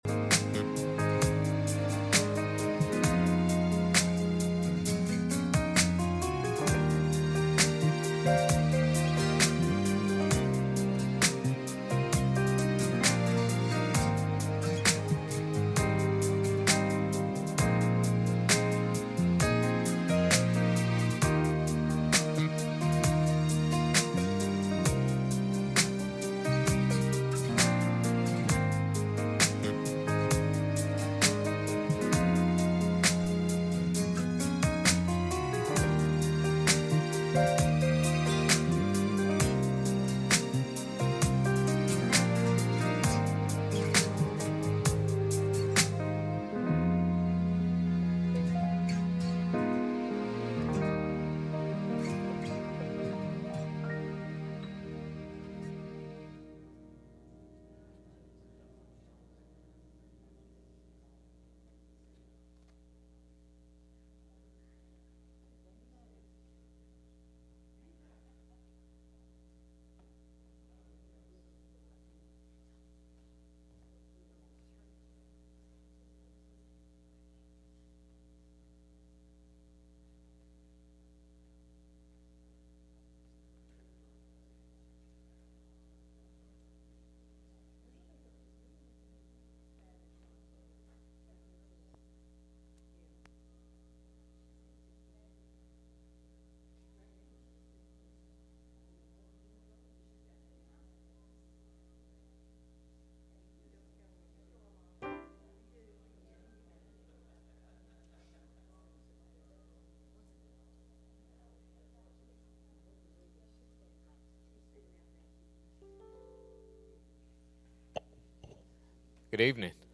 Passage: psalm 61 Service Type: Sunday Evening